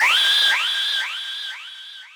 FX [ Siren ].wav